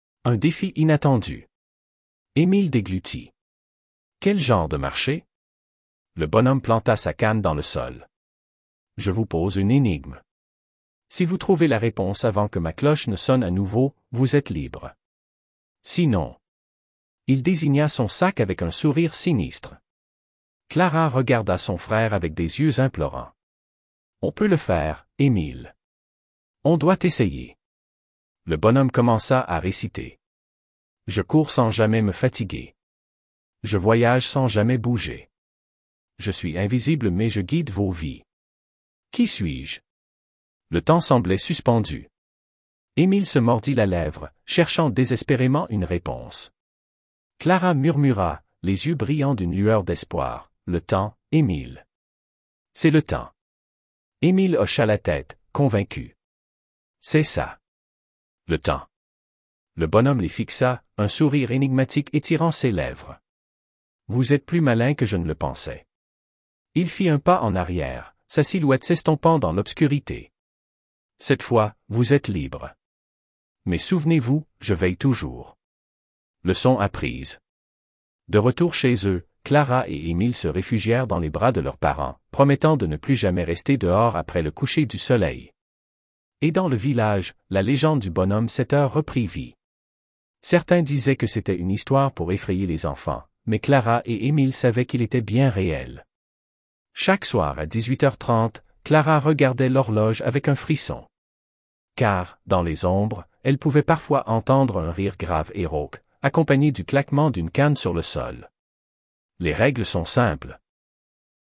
Merci IA